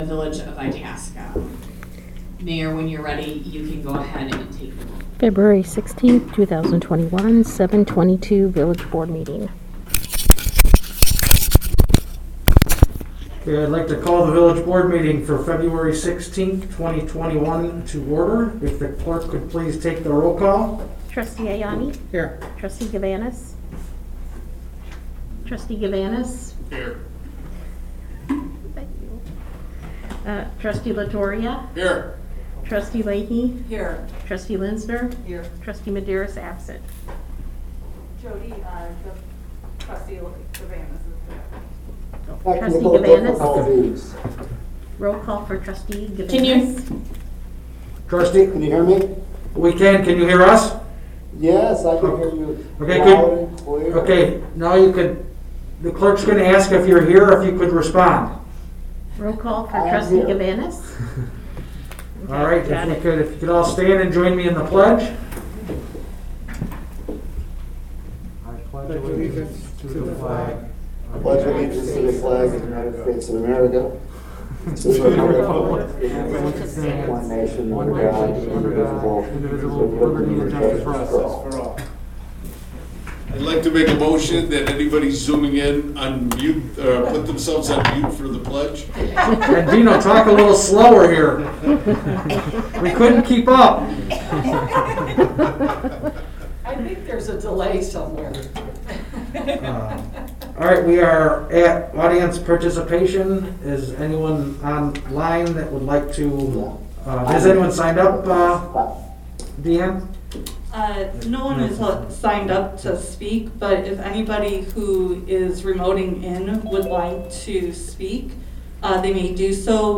Capital and Infrastructure Committee Meeting of Tuesday, February 16, 2021